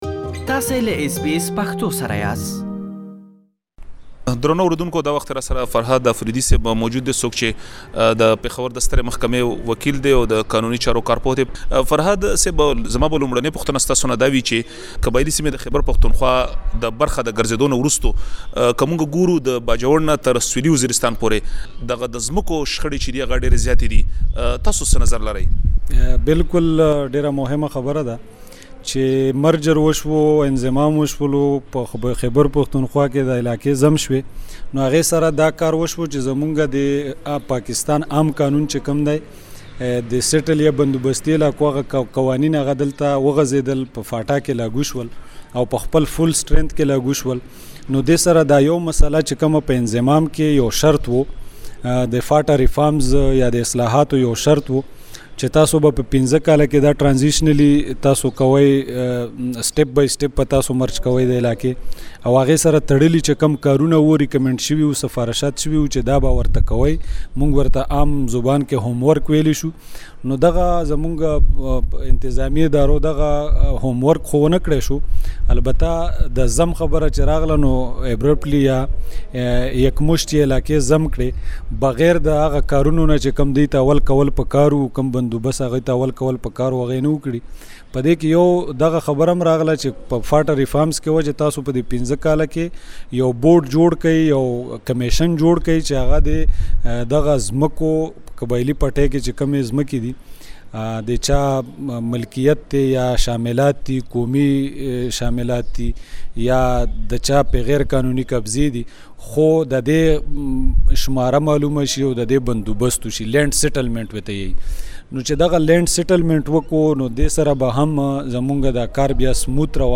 دا او نور معلومات پدې ځانګړې مرکې کې واورئ.